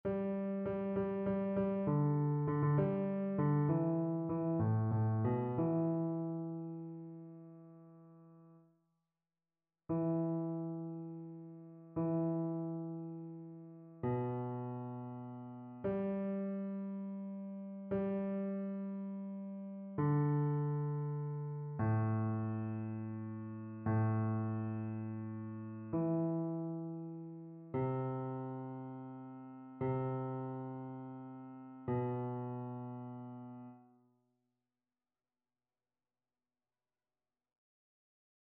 Basse
annee-c-temps-ordinaire-sainte-trinite-psaume-8-basse.mp3